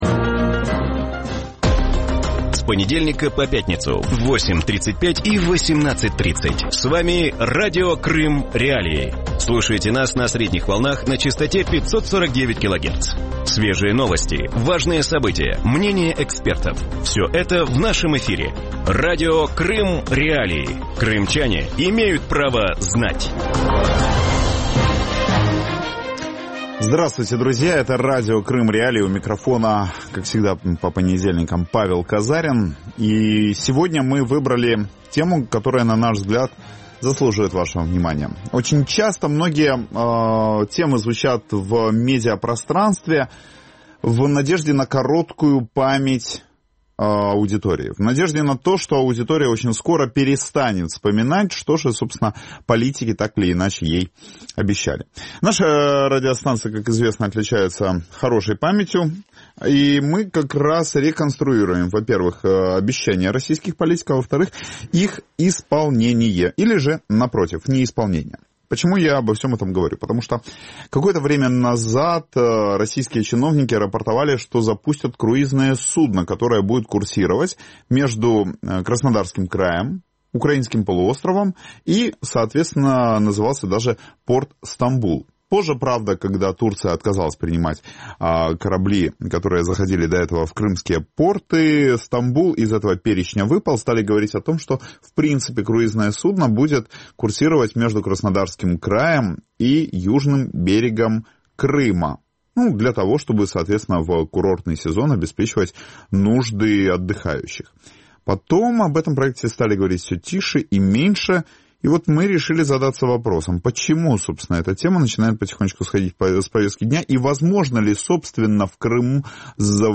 В вечернем эфире Радио Крым.Реалии обсуждают круизные маршруты Крыма.